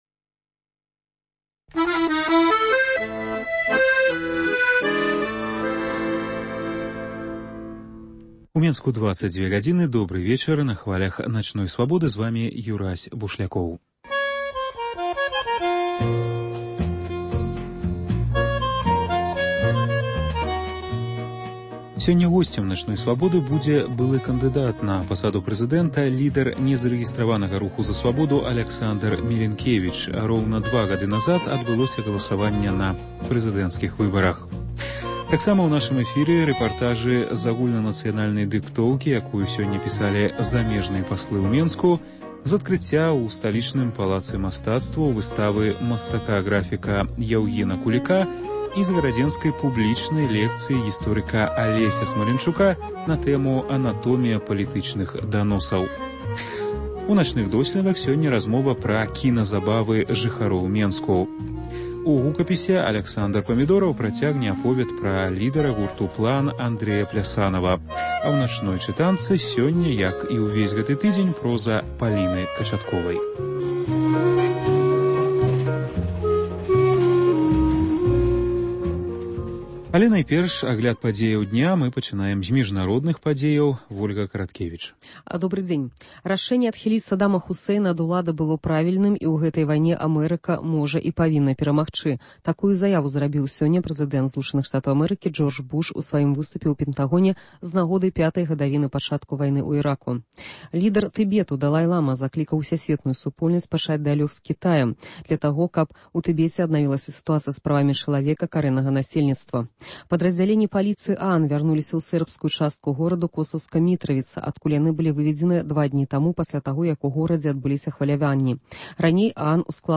Госьцем “Начной Свабоды” будзе былы кандыдат на пасаду прэзыдэнта, лідэр незарэгістраванага руху “За свабоду” Аляксандар Мілінкевіч.
У нашым апытаньні людзі на віцебскіх вуліцах скажуць, што ім больш за ўсё запомнілася з апошняй прэзыдэнцкай кампаніі.